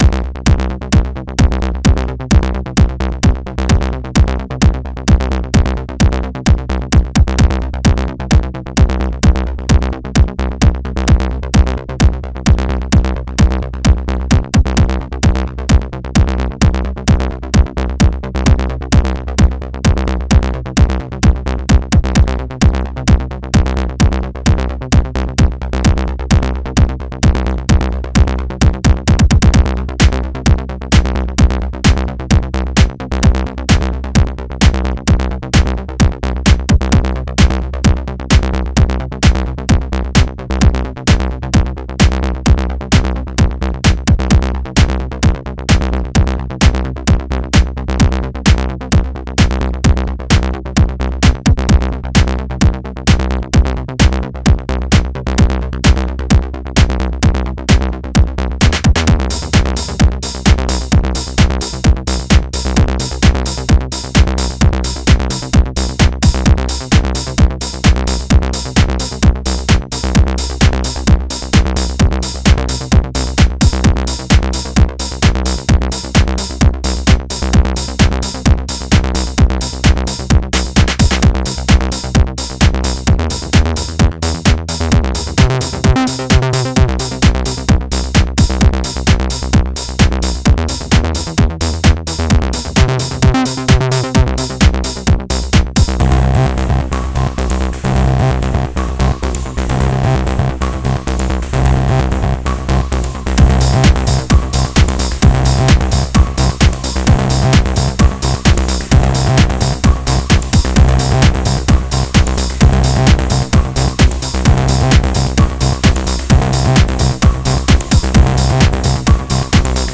vous avez rien contre l'acid ?
j'aime bien les sons mais ça manque d'énergie ça pète pas, mais pour un excercice de style oldschool c'est bien fait
je m'attendais à un truc plus musclé c'est tout
moi je trouve le son très bien et la compo très très sage !
C'eest pas le genre de tek qui me plait car trop lineaire et pas assez groovy.